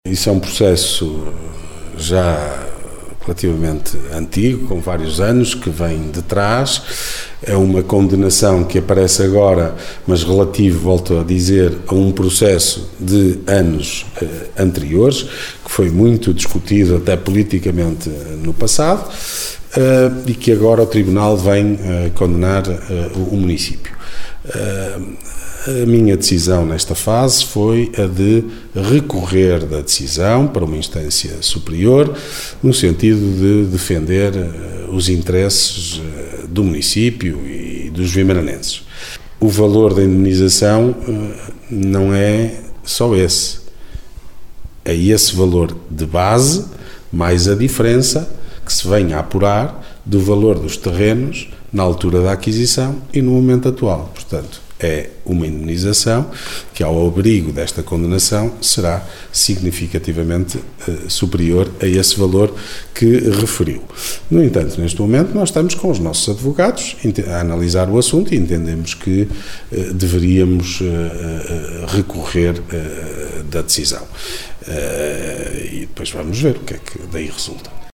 Declarações de Ricardo Araújo, presidente da Câmara Municipal de Guimarães. Falava à margem da reunião do executivo desta semana, depois deste caso ter estado também em discussão na última sessão da Assembleia Municipal.